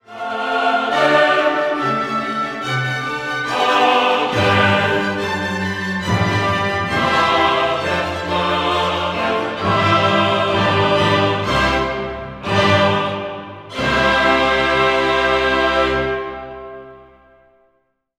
The Credo closes with a Type II chorus on “Et vitam venturi saeculi”.  The music is so jolly and exuberant, who could fail to be won over to belief in the “life of the world to come”?
Amen” the same music he had used for the repeated “Non” in “Non erit finis”, accompanied one last time by the frenetic string motif.